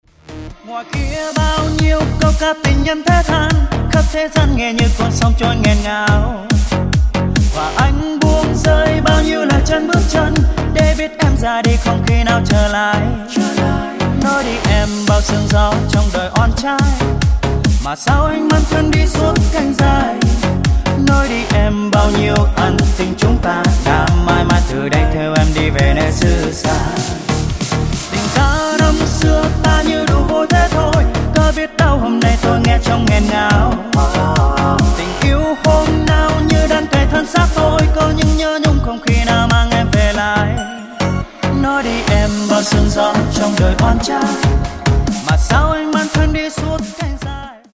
EDM/ Underground